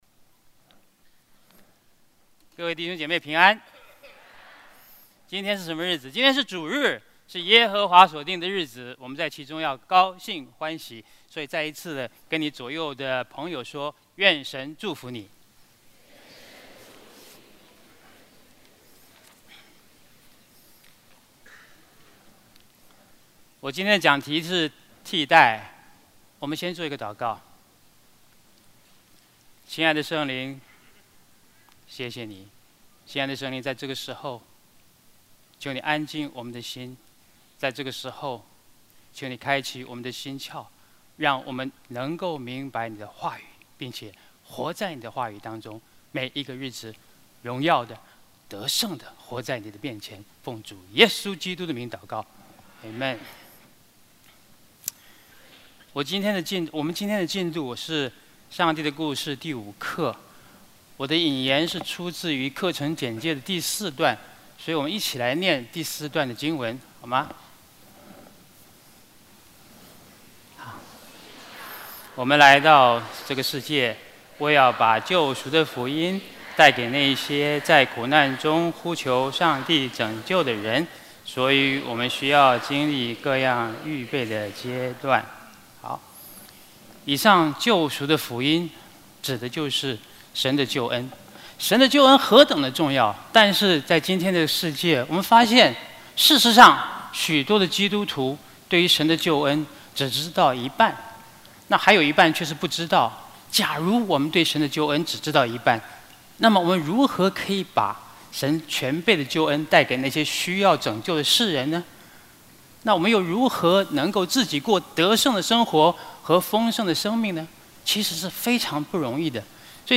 主日证道 |  上帝的故事（五）替代